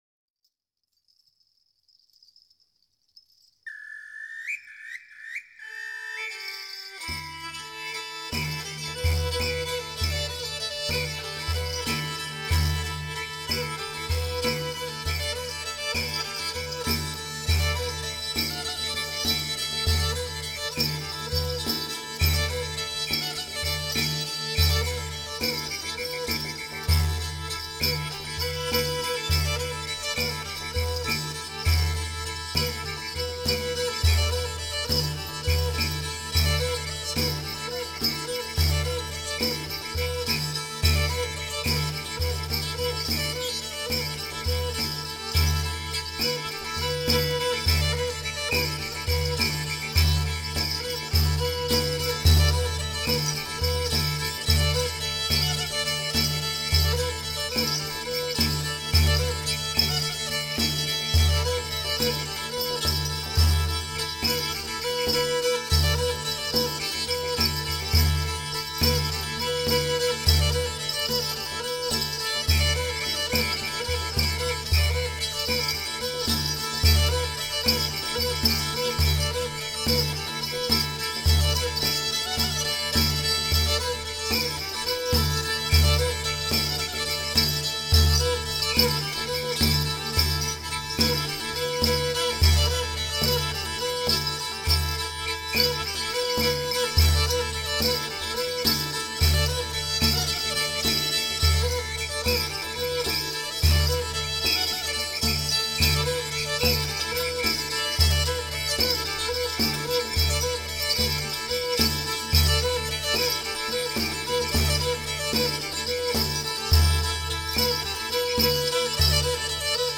Kemence, aka Black Sea Fiddle; featherweight 3-stringed fiddle of similar class to the Pontic Lyra - supported by thumb & palm of right hand & tuned in fourths. Hear it in a short Solo Improvisation , or in ensemble context with percussion & bird warbler in HORTUS CUPENDIS (Cupid's Garden).